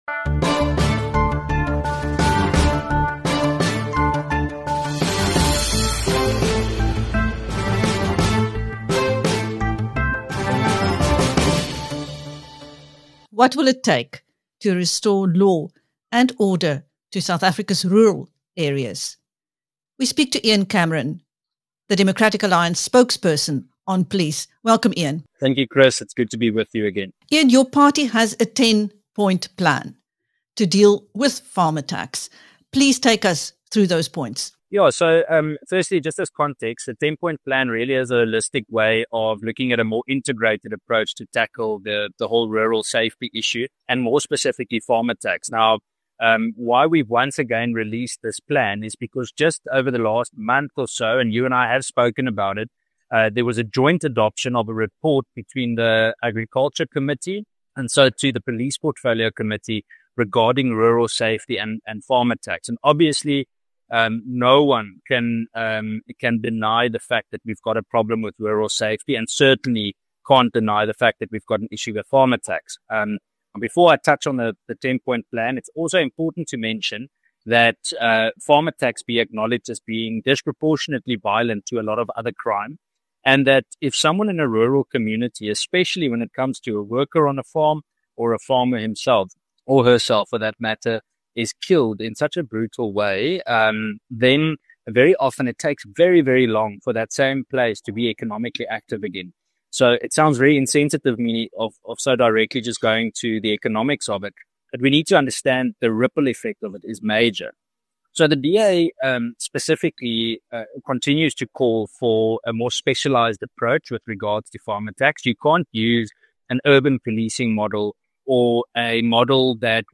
The Democratic Alliance (DA) has drawn up a 10-point plan to deal with farm attacks. In this interview with BizNews, Ian Cameron, the DA spokesperson on Police, says the plan will be submitted to the Minister of Police “and there's a lot of engagement ahead of us regarding rural safety”.